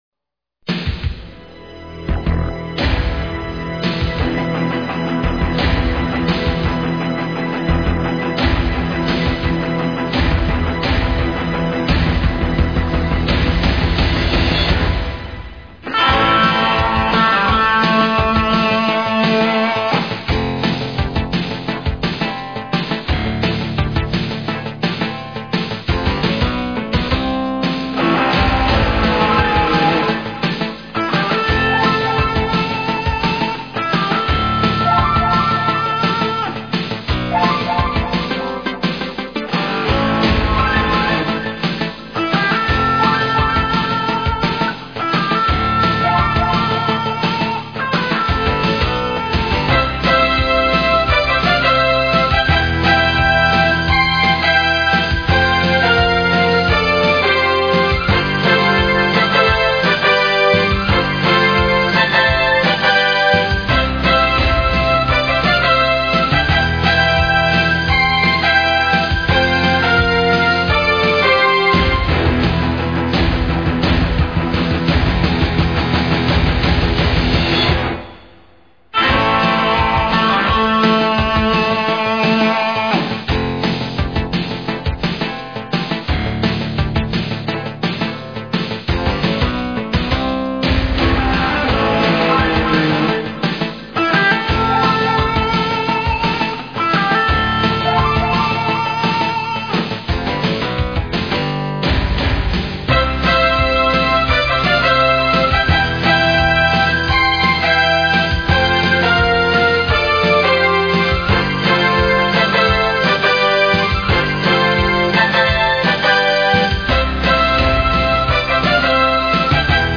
Long version of the theme.